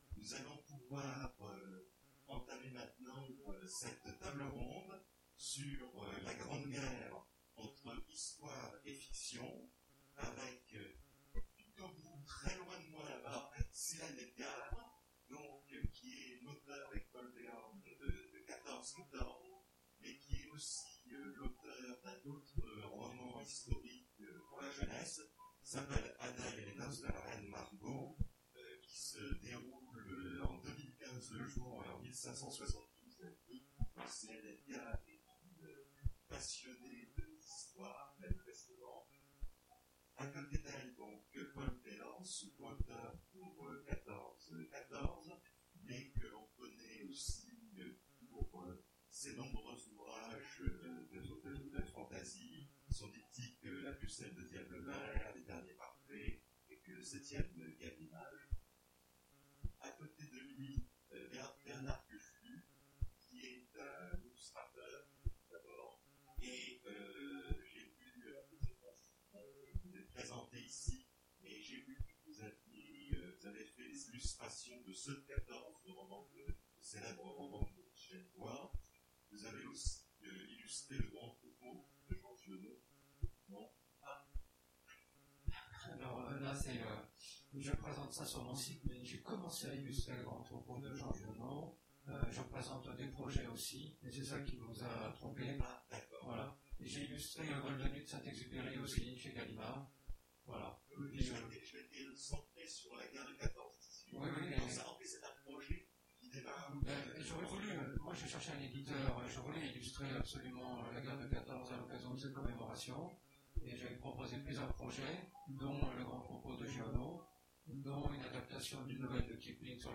Imaginales 2017 : Conférence La Grande Guerre... entre histoire et fictions